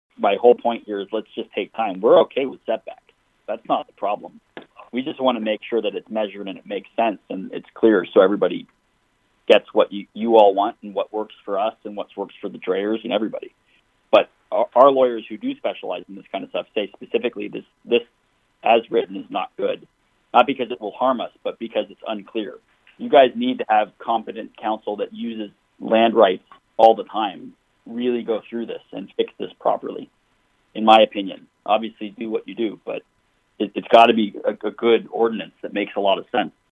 (Adair Co) The Adair County Board of Supervisors held another public hearing this (Wednesday) morning on a proposed ordinance regulating the placement of large scale commercial and industrial buildings.